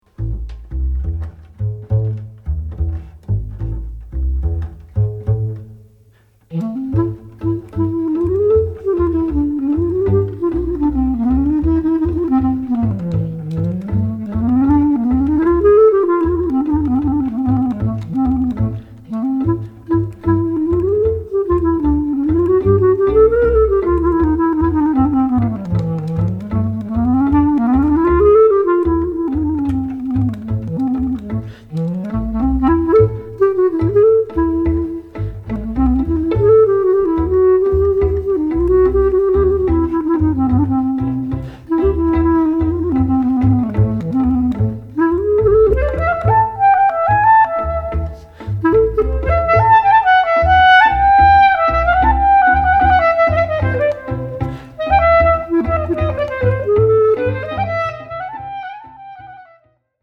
Genres: Klezmer, Jazz, World.
clarinet
violin
accordion
piano
bass
is particularly playful